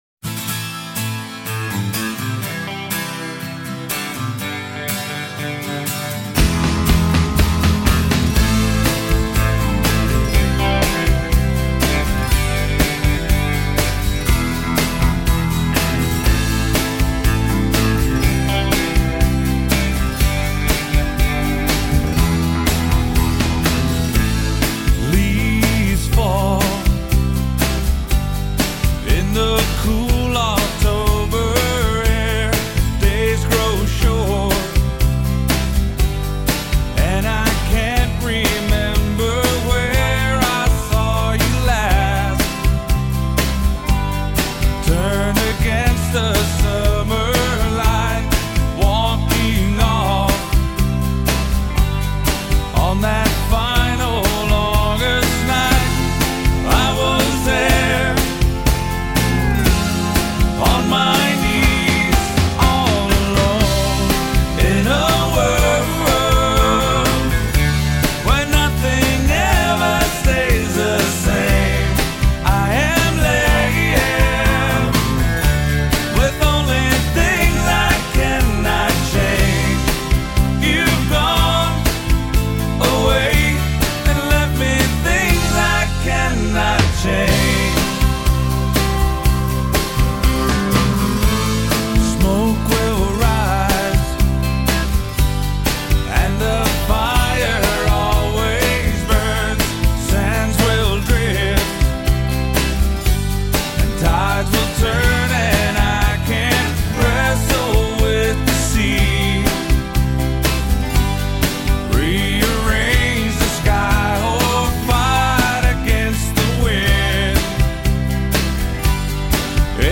golden-voiced front man